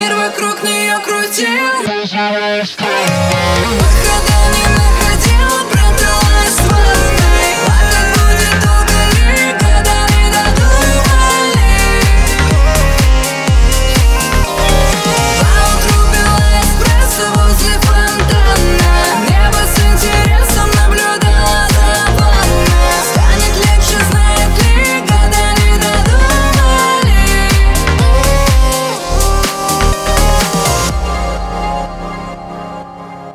• Качество: 320, Stereo
громкие
Club House